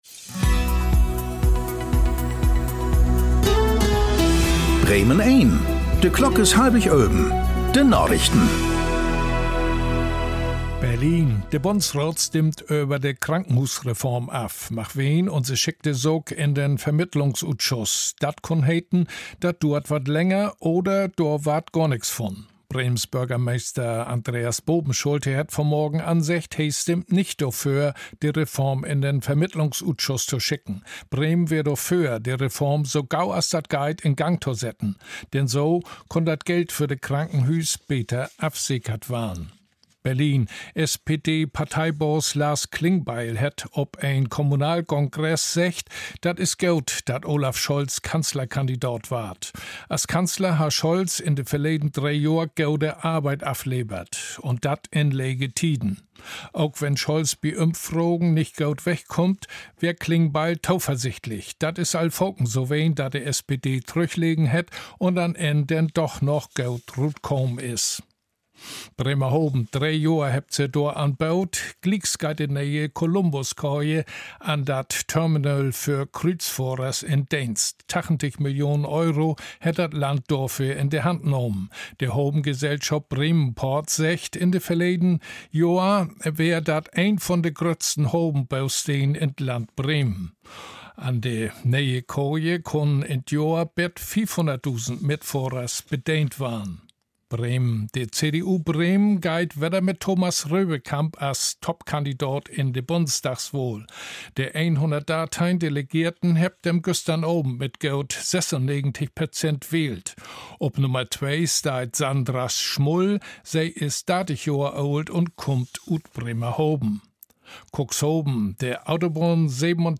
Aktuelle plattdeutsche Nachrichten werktags auf Bremen Eins und hier für Sie zum Nachhören.